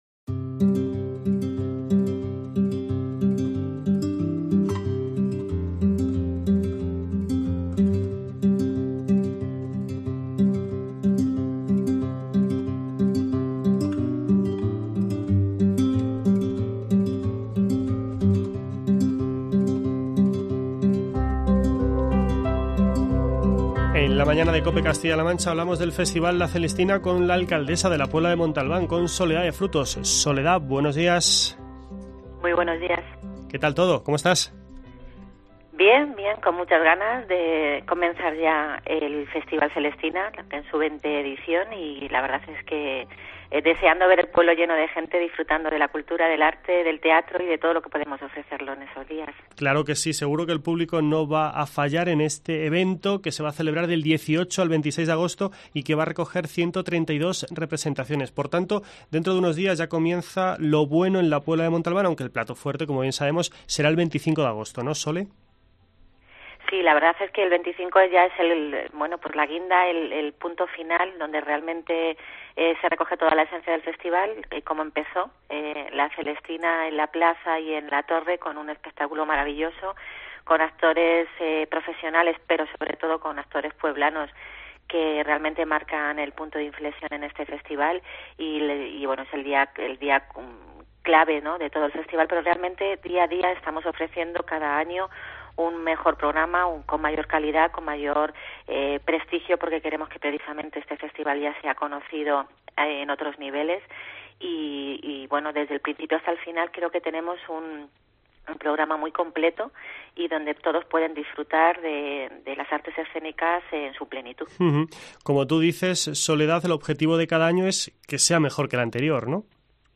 Charlamos con Soledad de Frutos, alcaldesa de La Puebla de Montalbán